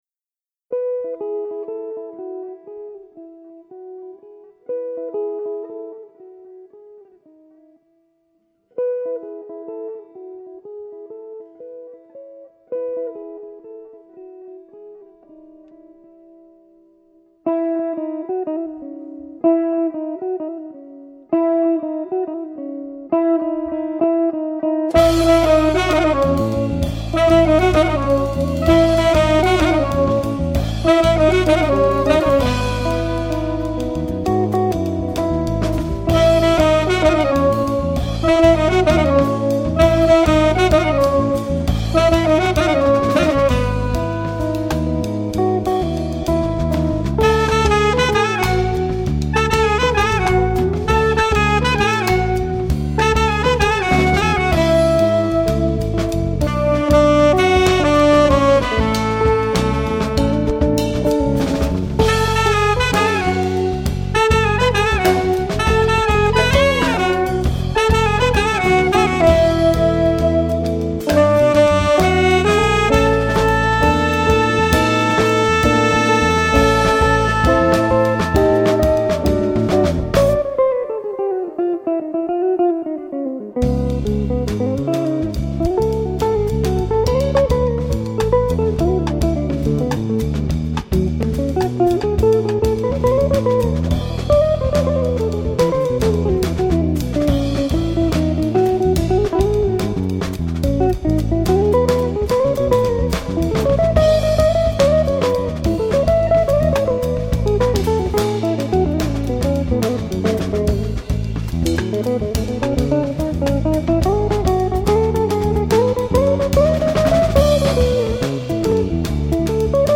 chitarra
batteria
basso
sax